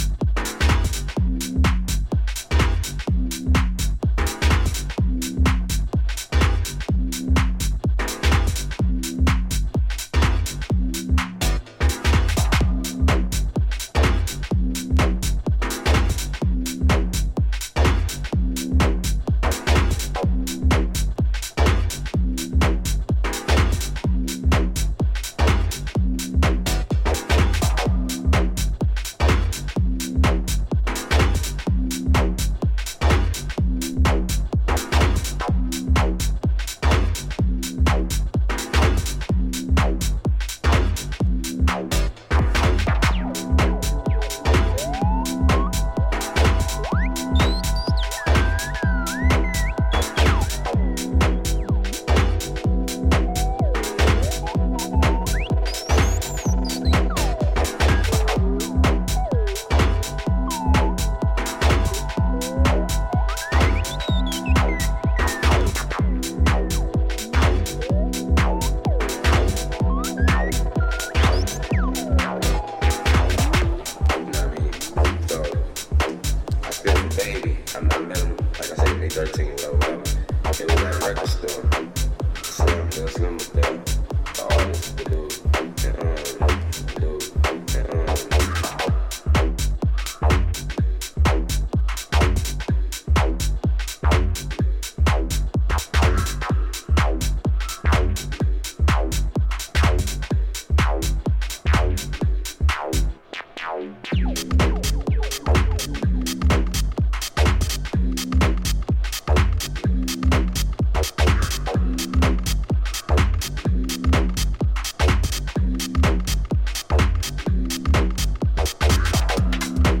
いずれの楽曲も当レーベルらしいカラーの、ソリッドかつ極めて圧の強いミニマル・ハウス！